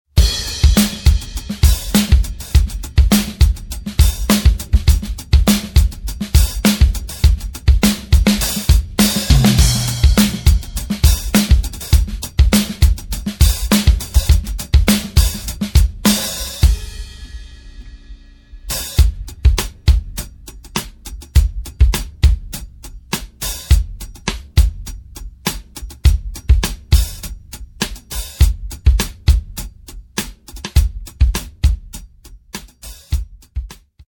The X-15 was setup in a X-Y configuration dead center over the drum kit. Std. transformer used on this MP3 sample.
Note:  A variety of other types of microphones were also used on this MP3 sample.